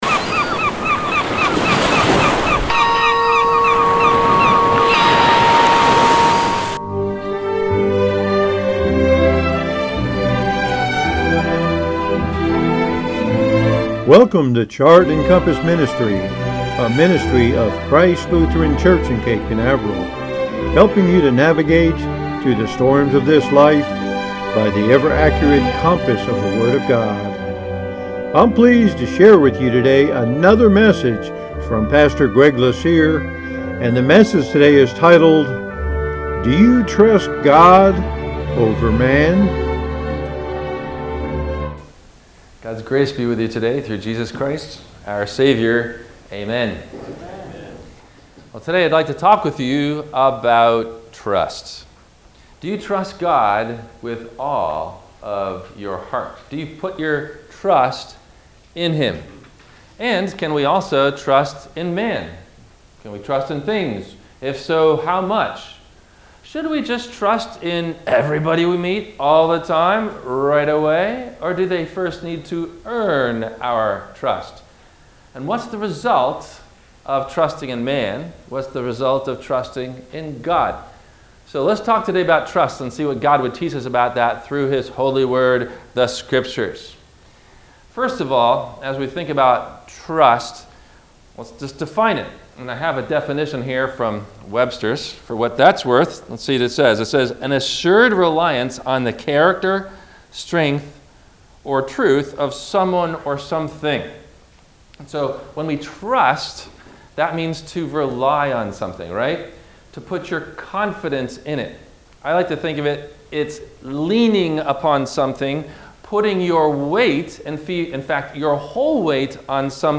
Questions asked before the Sermon message: